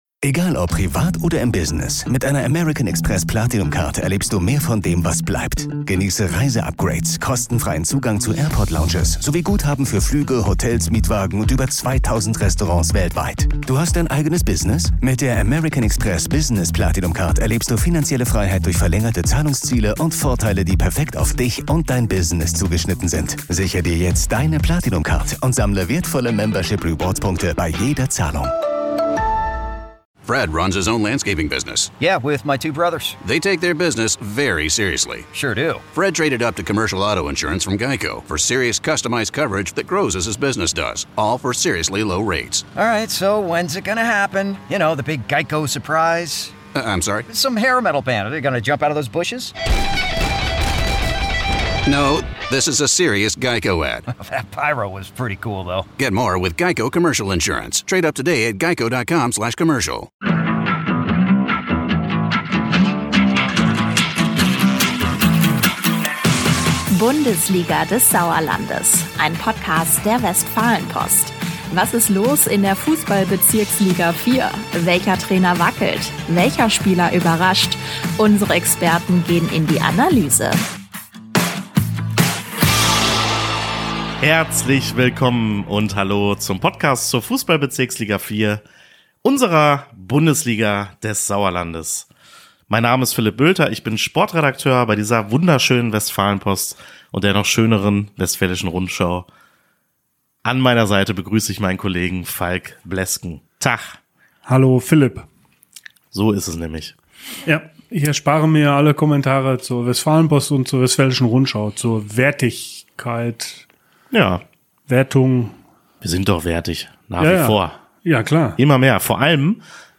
Beschreibung vor 1 Jahr Ist aus dem Dreikampf an der Spitze nun ein rein bilaterales Duell zwischen Tabellenführer Fatih Türkgücü Meschede und Verfolger SG Bödefeld/Henne-Rartal geworden? Die Sportredakteure